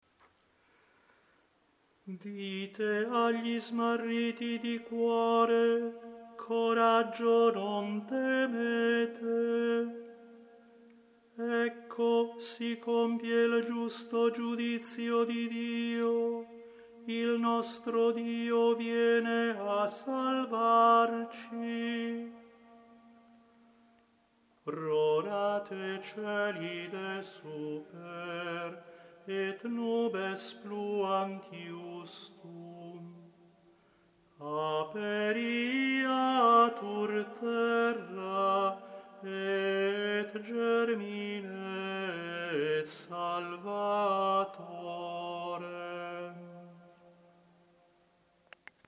Si propone un tono salmodico ad hoc per ogni domenica di Avvento, che possa ben adattarsi alla semplice e magnifica antifona ambrosiana Rorate Coeli.